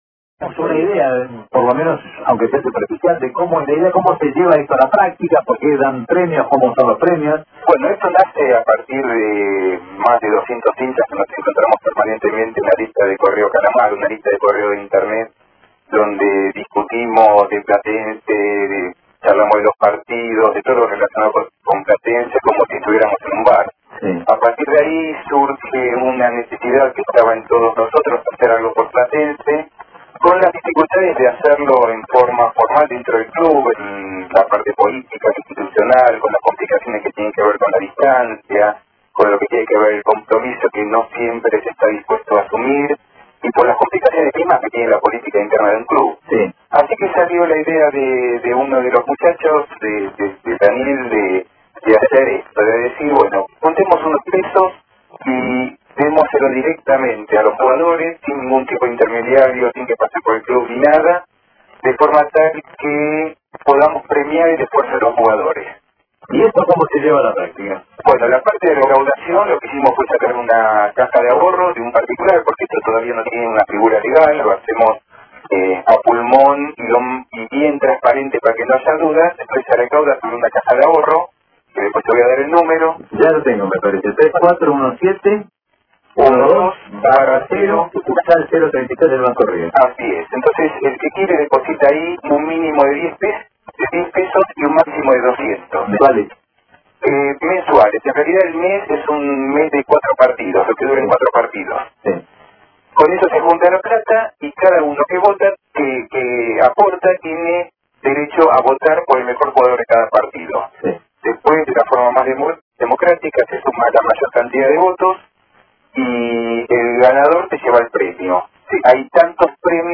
Reportaje en vivo con comentarios y felicitaciones a Estímulo Calamar (formato mp3)